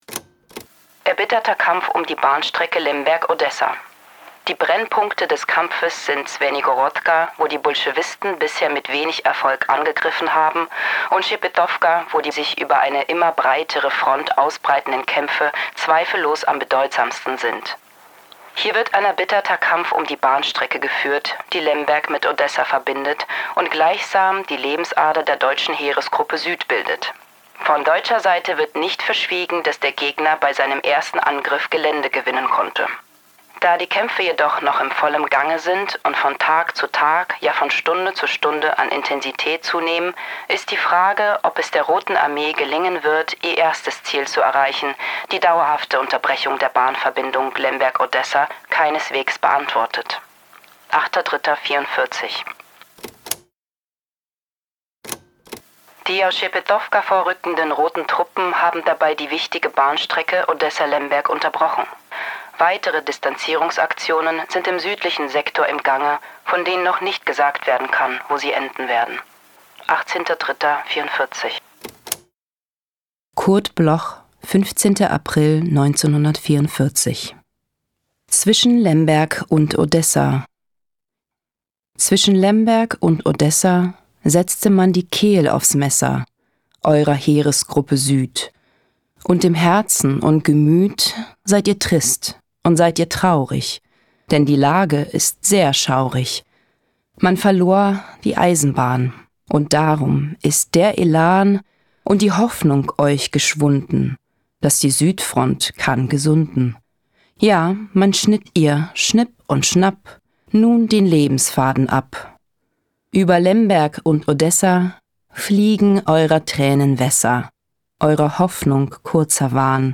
Recording: Alias Film und Sprachtransfer, Berlijn · Editing: Kristen & Schmidt, Wiesbaden
Alina Levshin (* 1984) is een Duitse artieste.